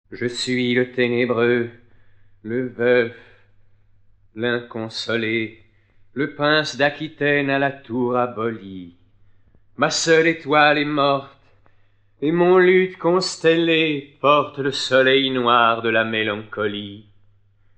Get £1.29 by recommending this book 🛈 Ces poèmes sont lus par Gérard Philipe .